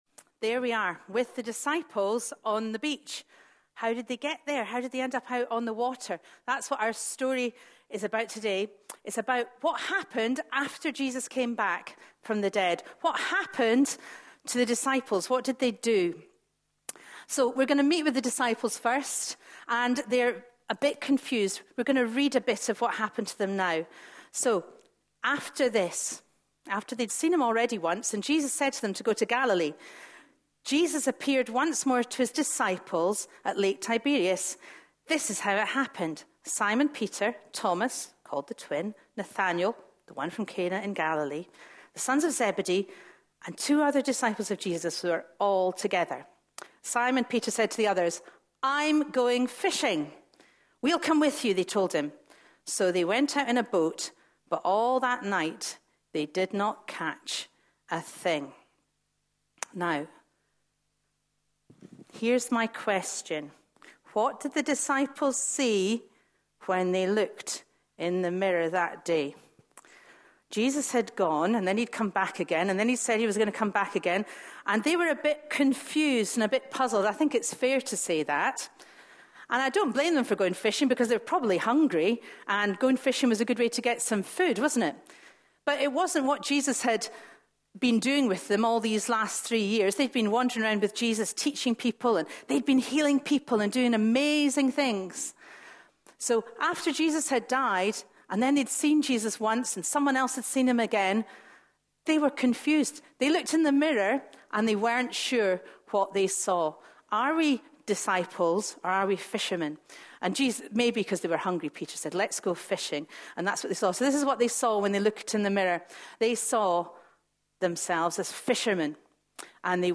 A sermon preached on 27th April, 2014.